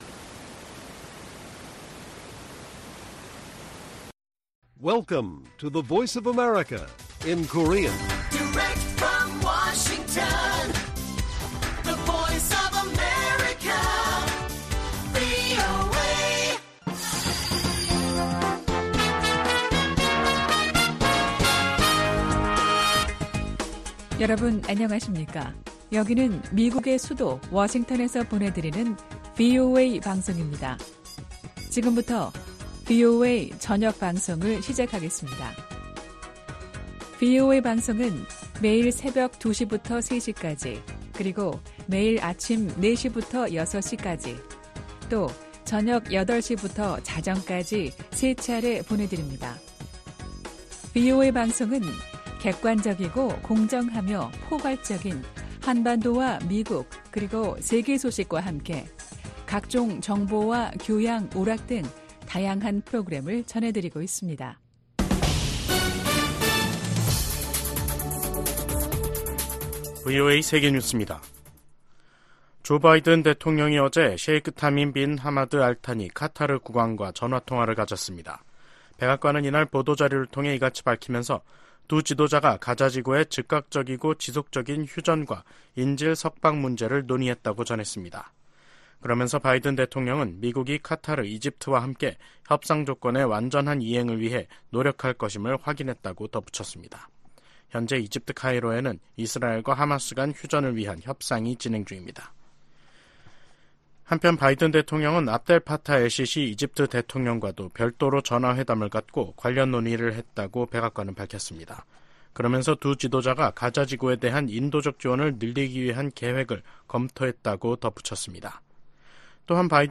VOA 한국어 간판 뉴스 프로그램 '뉴스 투데이', 2024년 4월 30일 1부 방송입니다. 토니 블링컨 미 국무장관은 중국과, 북한, 이란이 우크라이나와 전쟁 중인 러시아를 직 간접적으,로 지원하고 있다고 비판했습니다. 북한 무기를 운반한 러시아 선박이 중국 항구에서 발견된 가운데 미국 정부는 중국과 러시아의 협력 관계를 심각하게 보고 있다고 강조했습니다. 한국 외교부 장관이 다음달 중국을 방문해 한중 외교장관회담을 갖는 일정이 추진되고 있습니다.